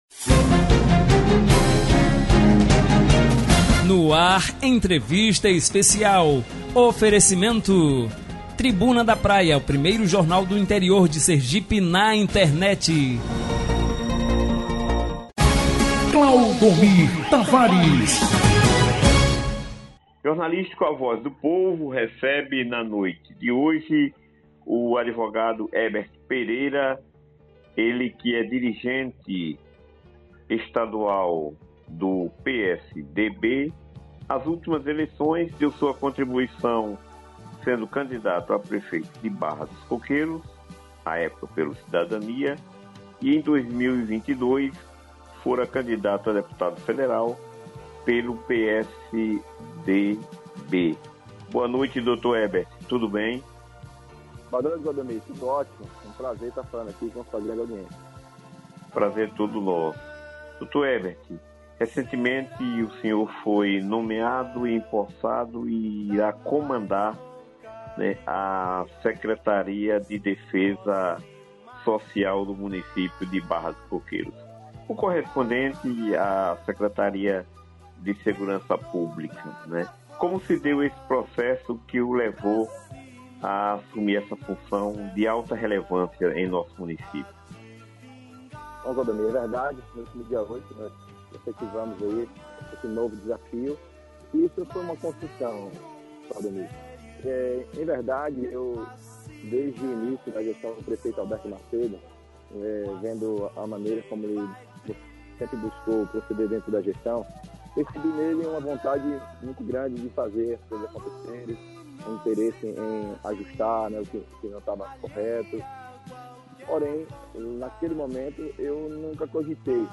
ÁUDIO: Entrevista com HEBERT PEREIRA, secretário municipal de Defesa Social de Barra dos Coqueiros
Confira abaixo na íntegra os áudios da entrevista liberados na manhã de hoje pela emissora: